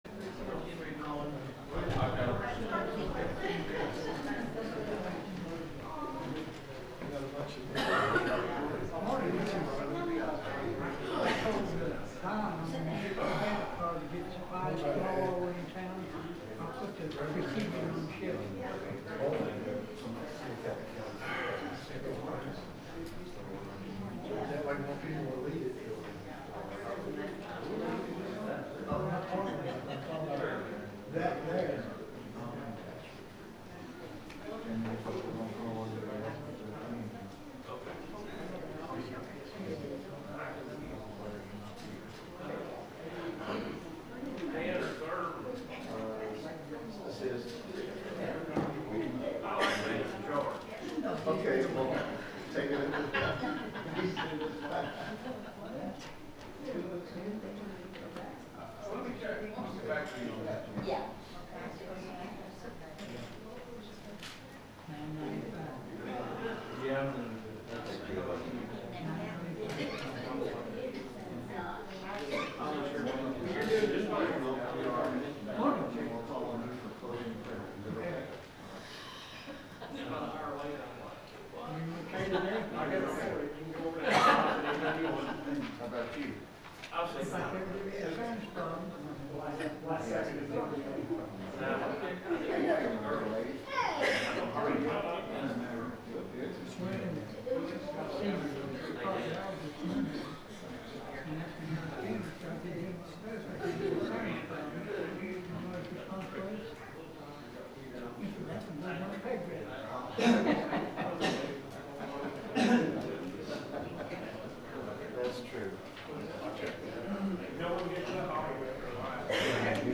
The sermon is from our live stream on 1/18/2026